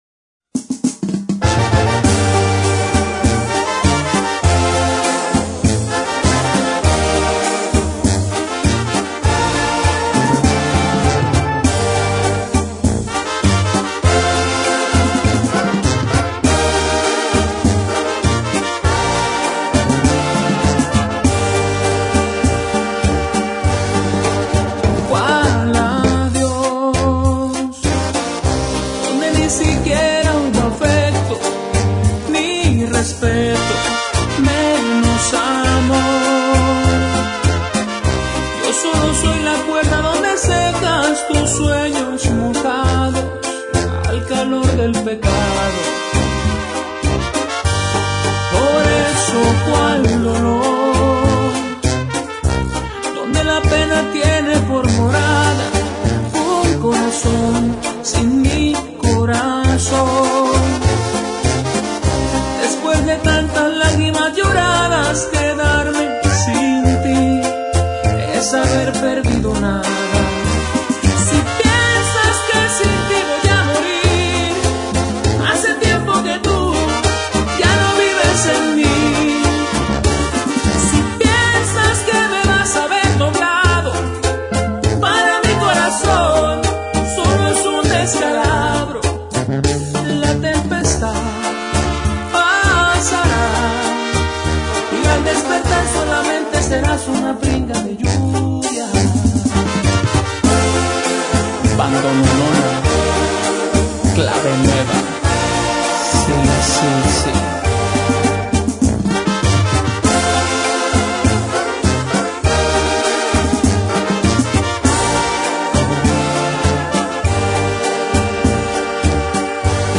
Boleros